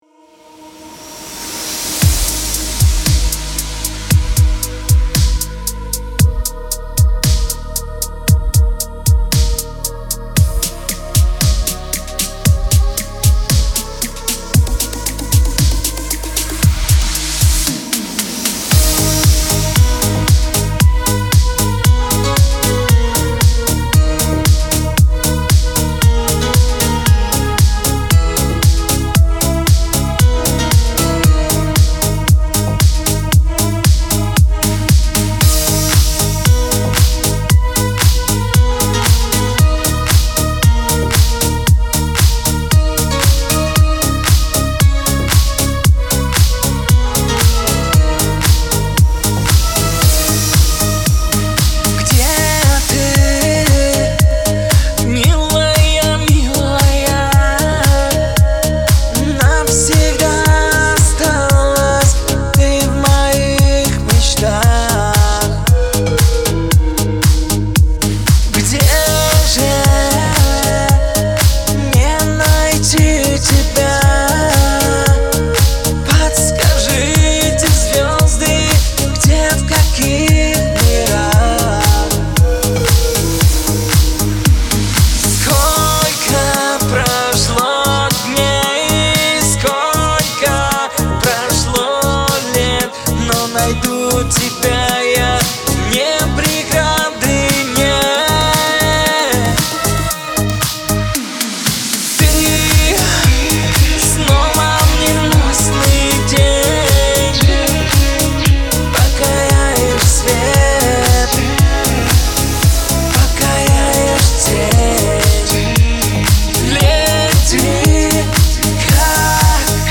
Жанр: Шансон, Поп, Русская Эстрада